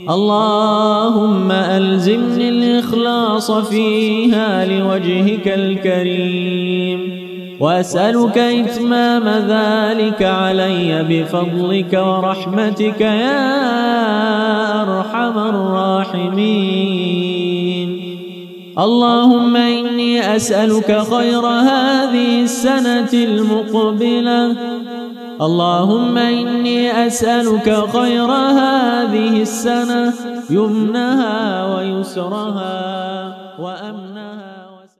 ادعية